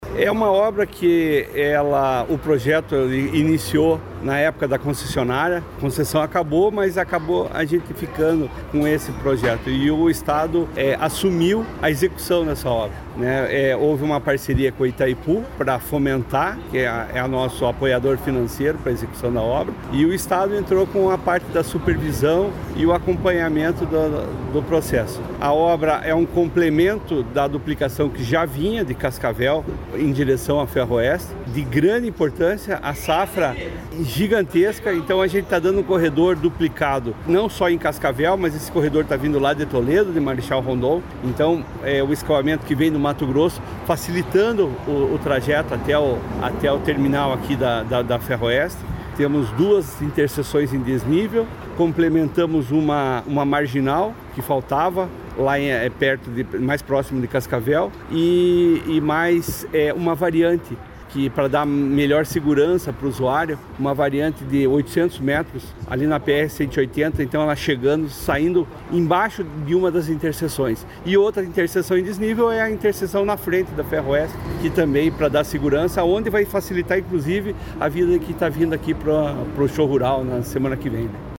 Sonora do superintendente regional do DER, Charlles Júnior, sobre a entrega da duplicação de 5,8 km da BR-277 em Cascavel